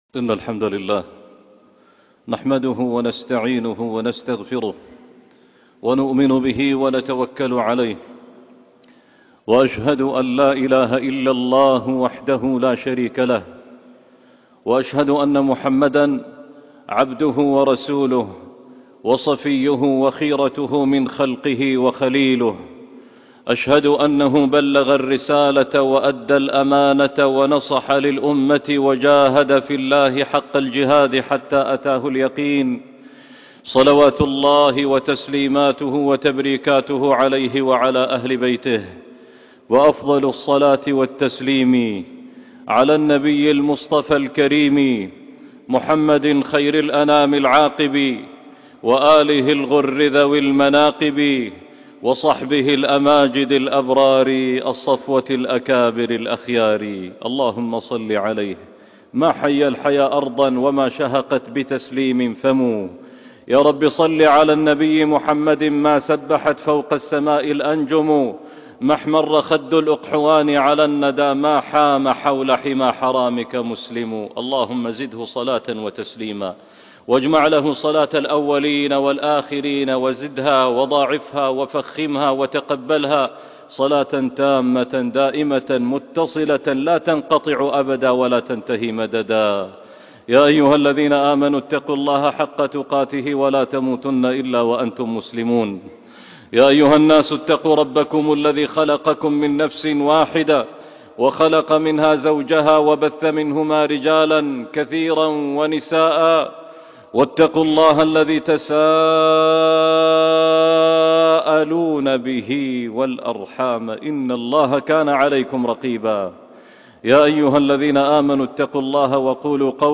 فتهجد به - خطبة وصلاة الجمعة